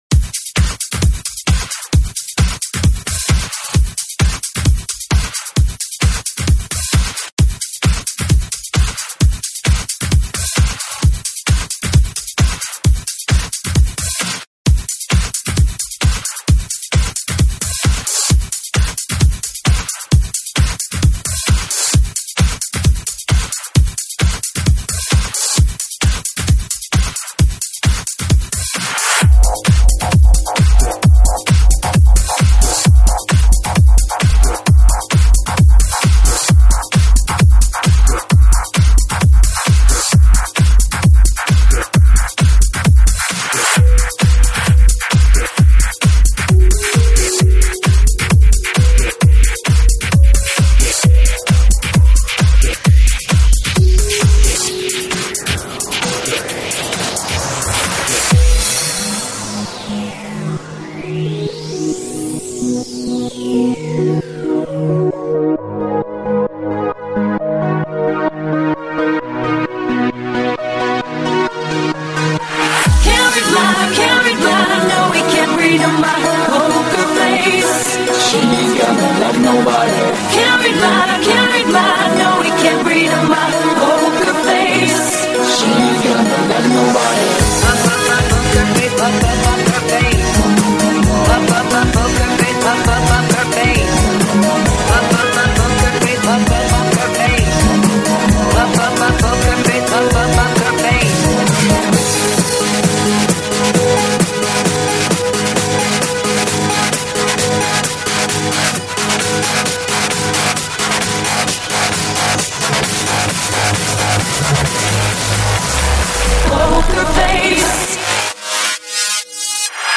Жесткий электро хаус, разбавленный не менее жестким электро.
Стиль: Electro House / Electro